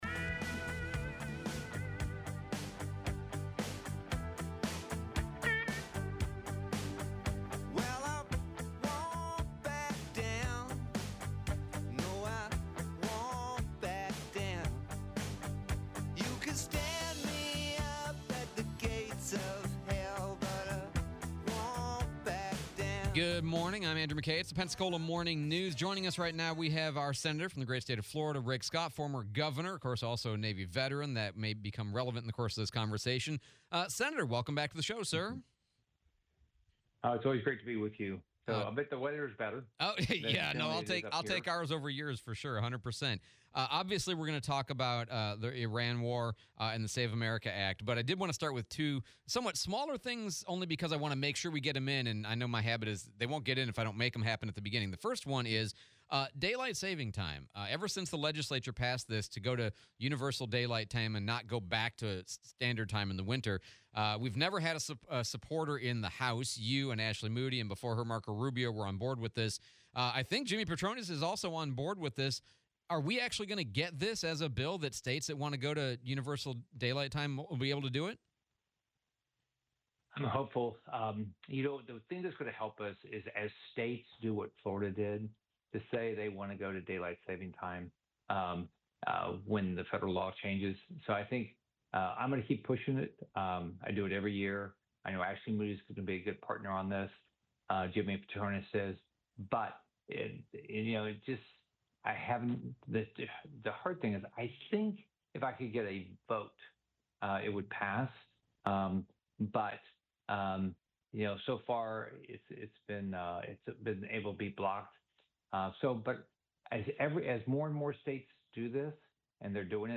03/12/26 US Senator Rick Scott interview